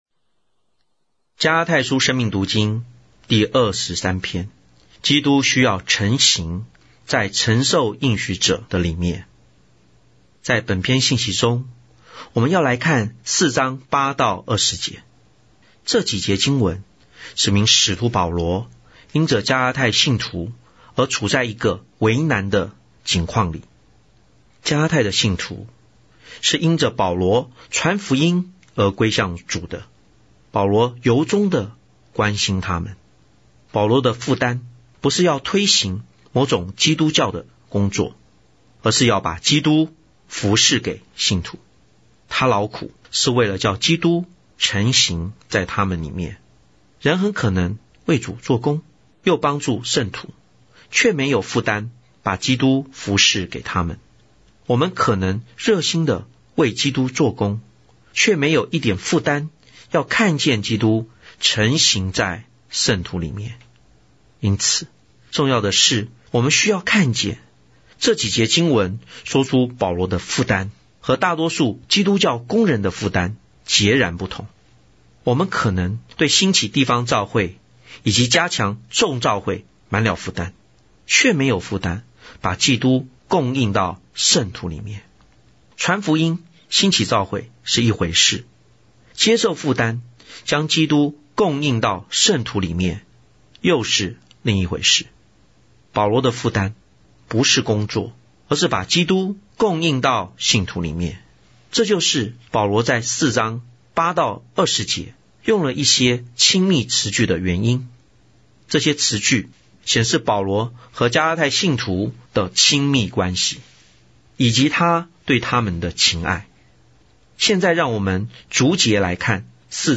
追求日期 10/22/2025👆加拉太書生命讀經 第二十三篇全篇👆延伸讀經段落及註解：加四8∼12及註🔉語音播放生命讀經📃新約聖經恢復本(紙本)：P799📃生命讀經(紙本)：P250L11~P254L11